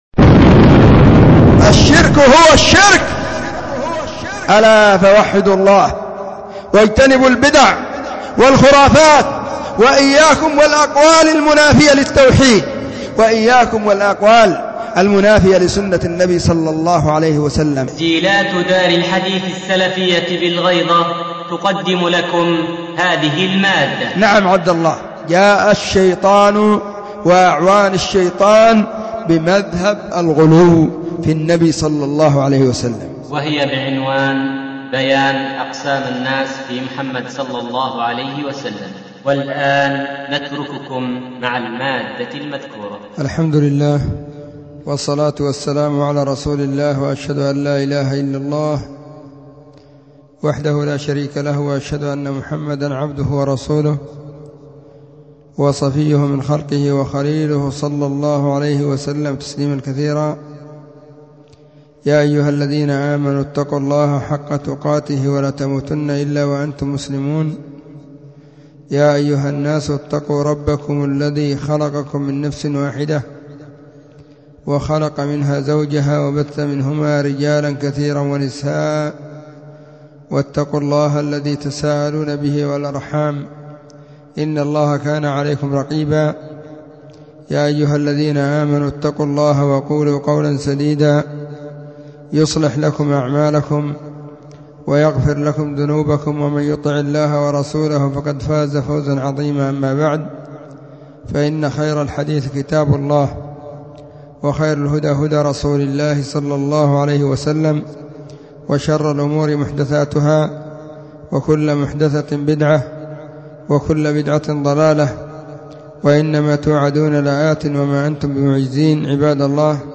محاضره
📢 مسجد الصحابة – بالغيضة – المهرة، اليمن حرسها الله